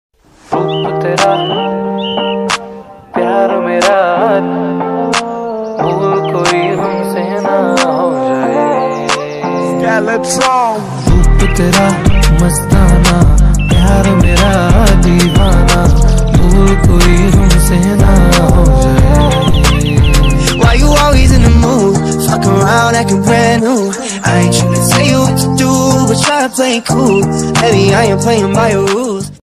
Exhaust Sound